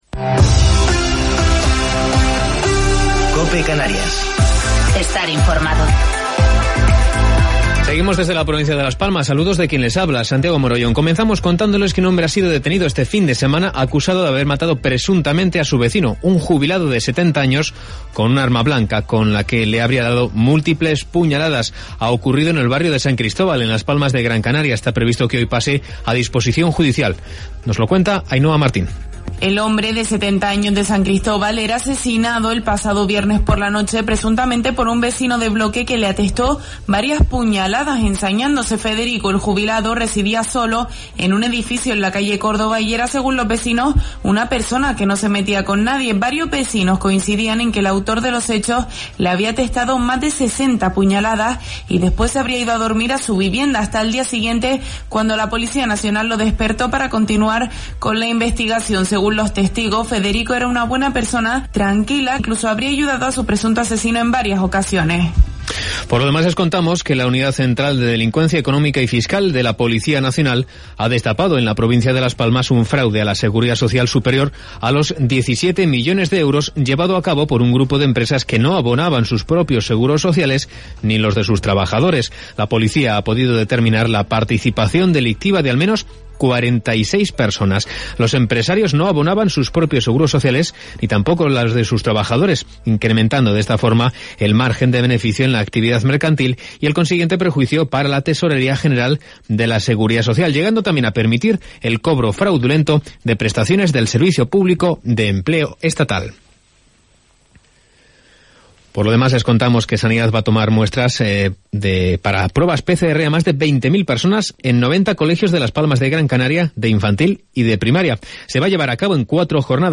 Informativo local 6 de Octubre del 2020